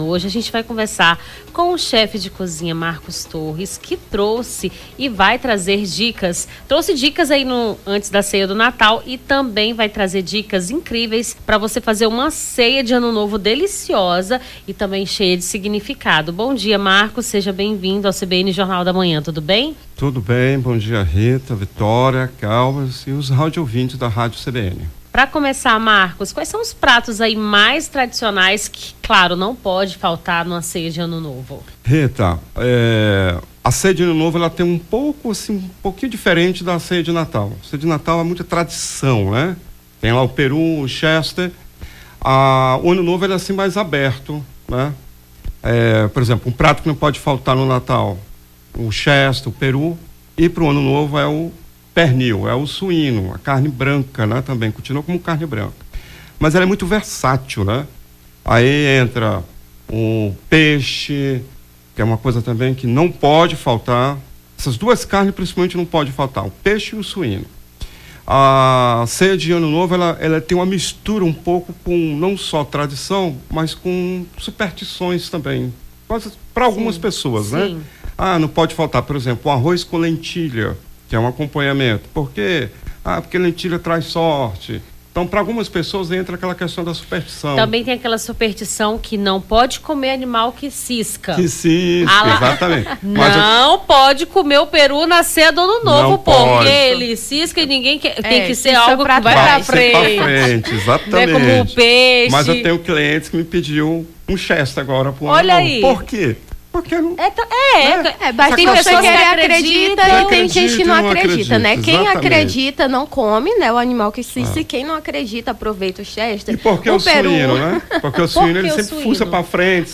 as apresentadoras
chef de cozinha
Nome do Artista - CENSURA - ENTREVISTA COMO PREPARAR CEIA DE ANO NOVO (30-12-24).mp3